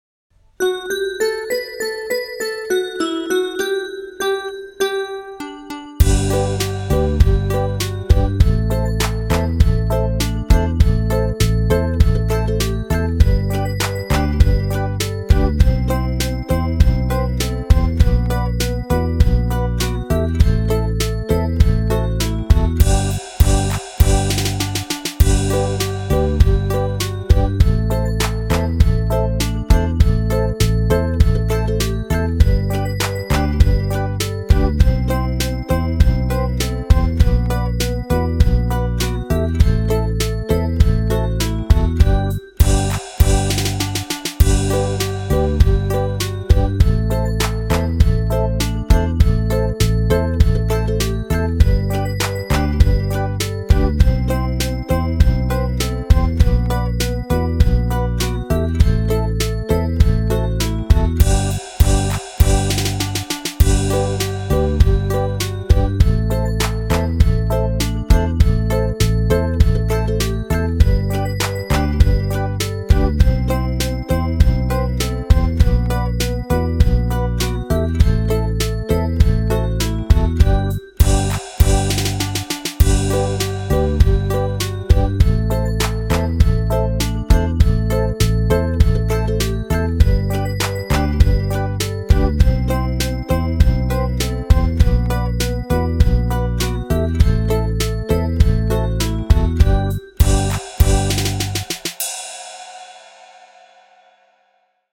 B1-AT-14-MP3_ល្បែងពេលសប្បាយ_If-You-Are-Happy-Song-Activity.mp3